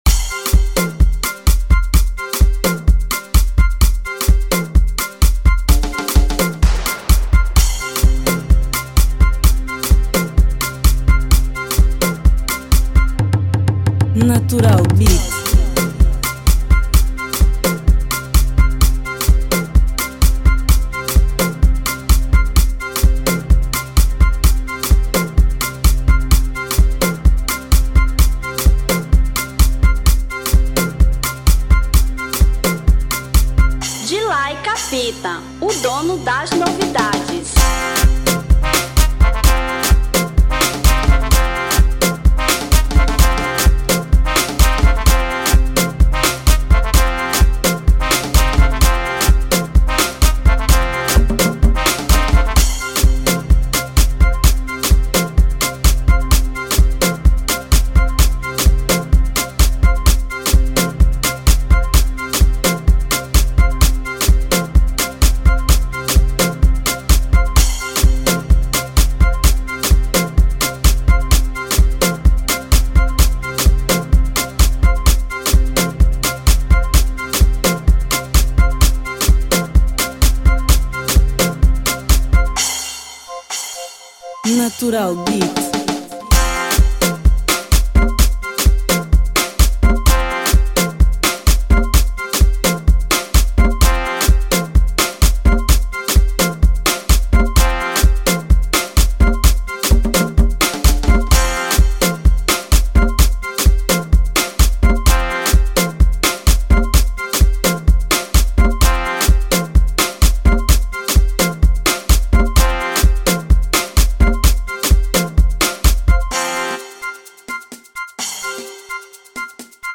Instrumental 2015